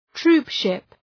Προφορά
{‘tru:p,ʃıp}